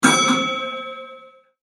Den Tram ass en Instrument vum Orchester.
Sample_Tram_1.mp3